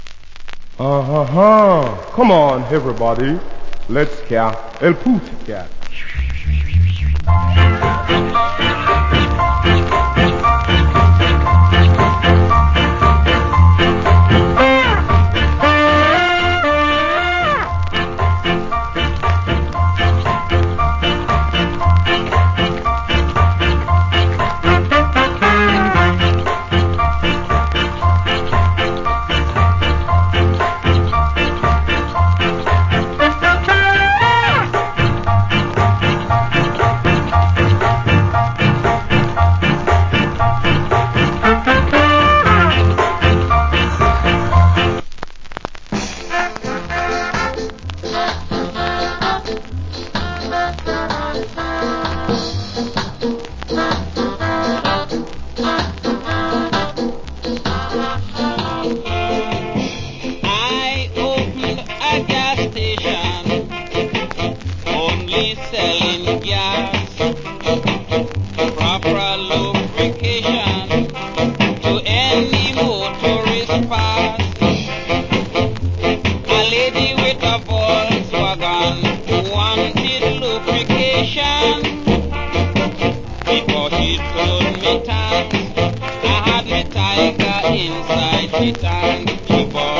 Foundation. Ska Inst.